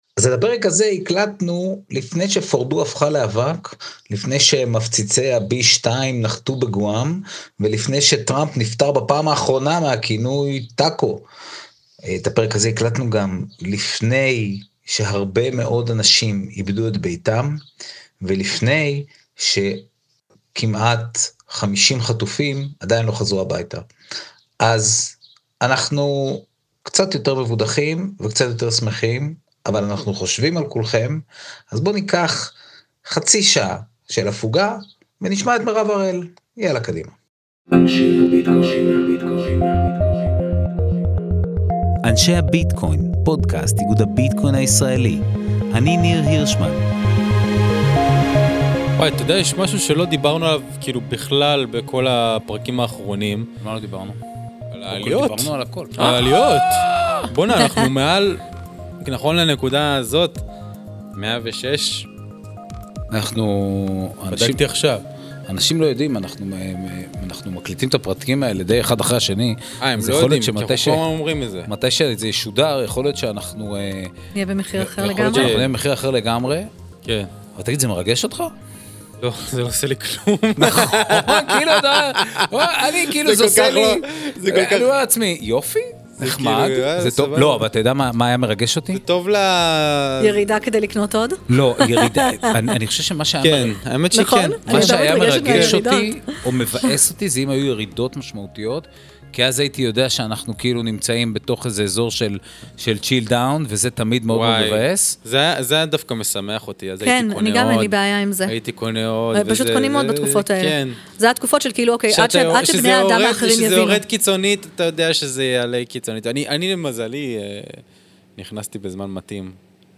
לשיחה על פינטק, רגולציה, עתיד הכלכלה – ומקומה של ישראל בזירה העולמית.